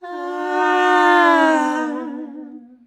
AAAH PITCH.wav